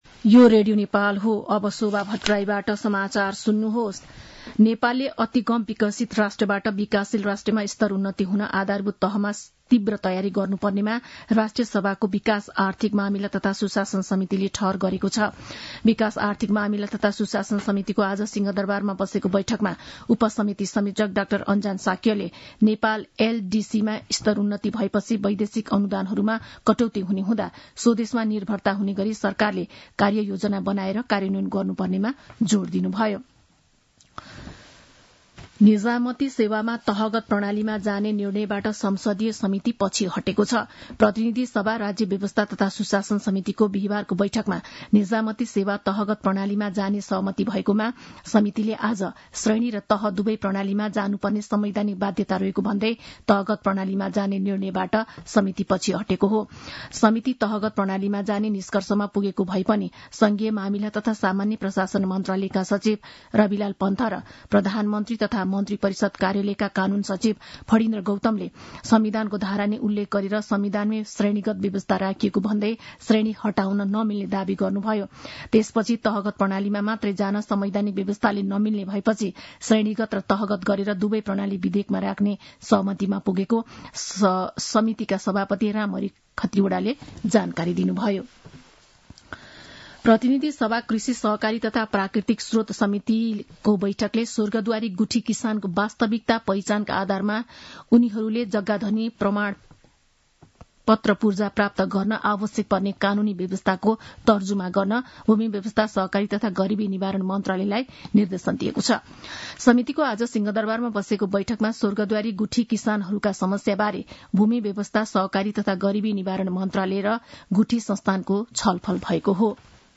साँझ ५ बजेको नेपाली समाचार : ३ फागुन , २०८१